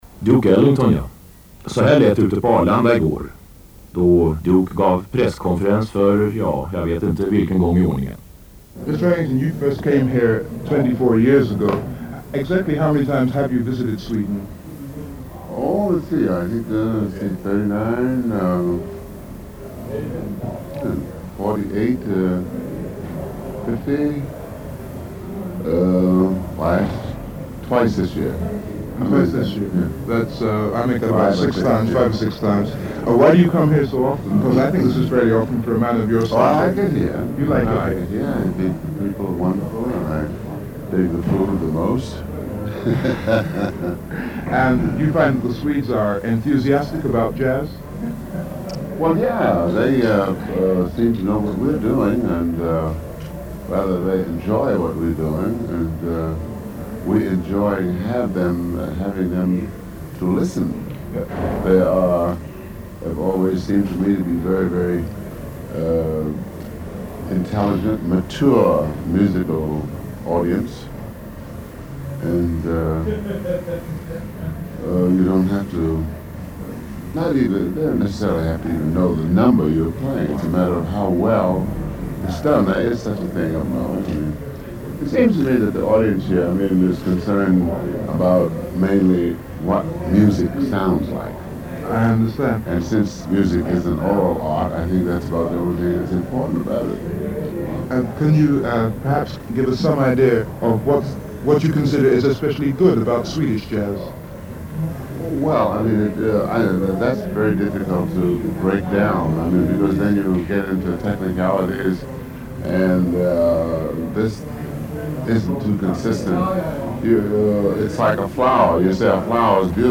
He arrived at the Stockholm-Arlanda airport on May 31, where Swedish Radio made a short interview with him.